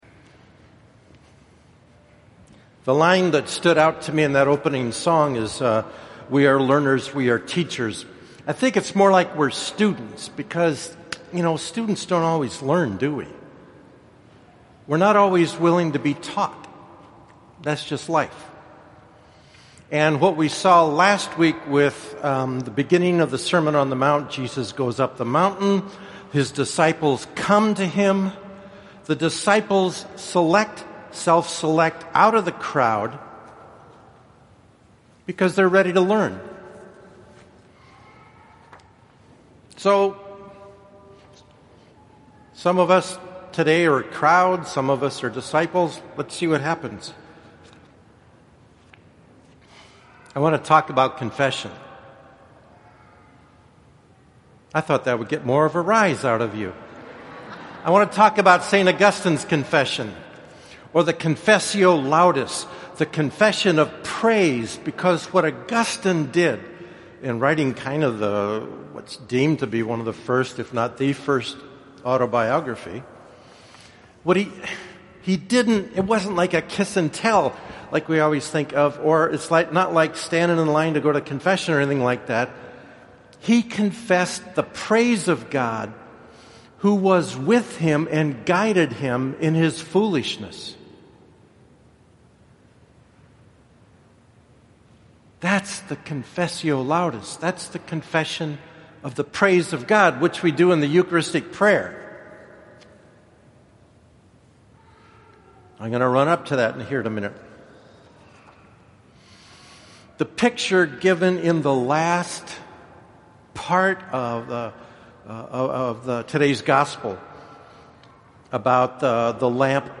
5th SUN ORD – audio version of homily